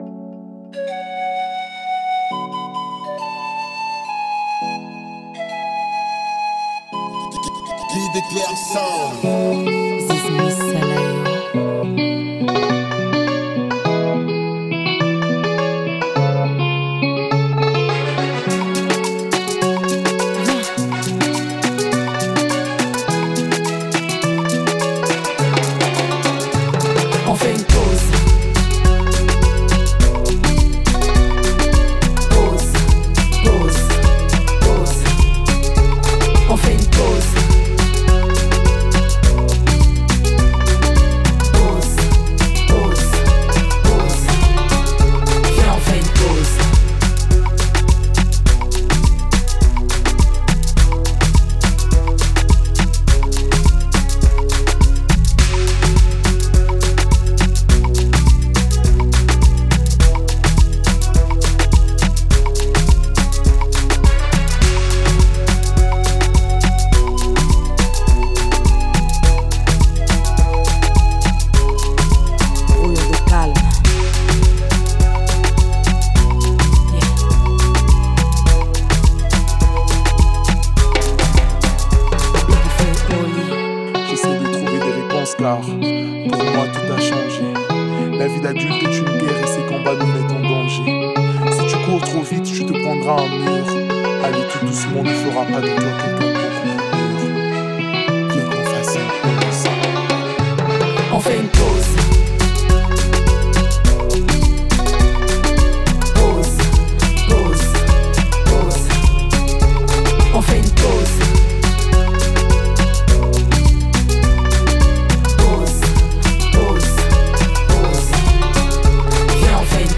À travers ses albums de slam